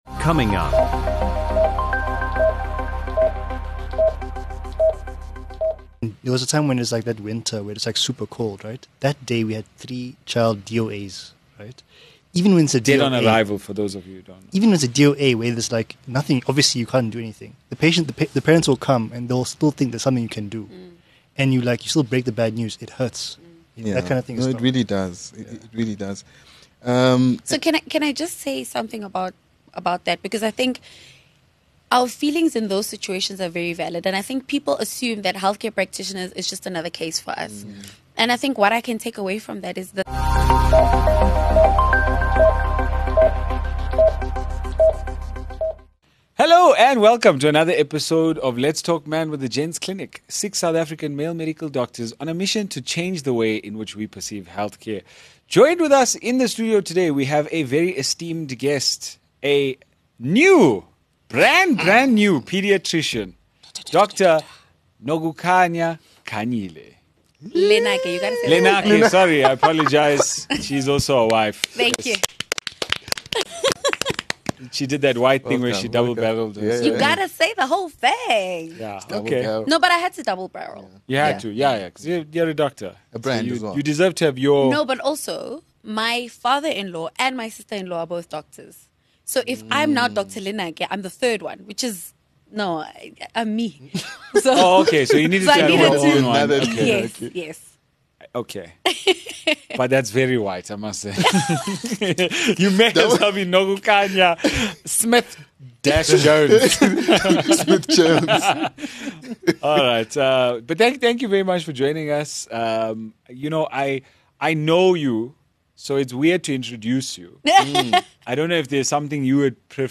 In this powerful and thought-provoking discussion